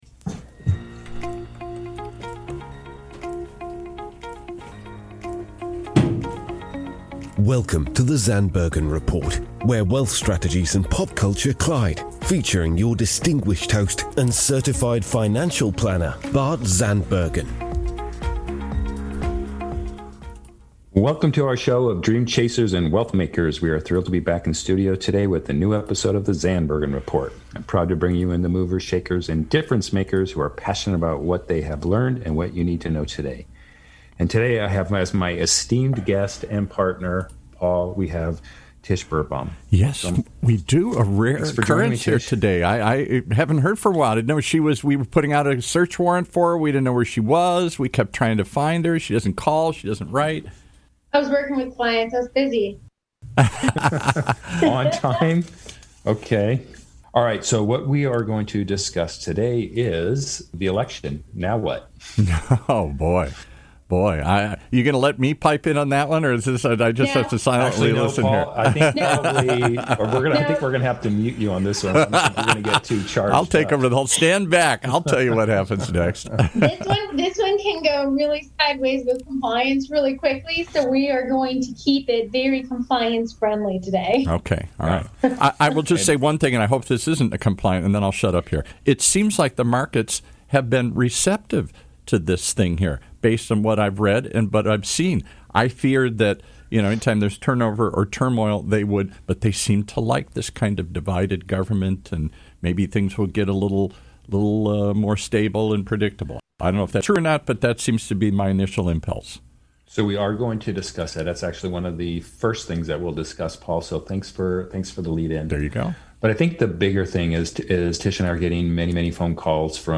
was joined in the virtual studio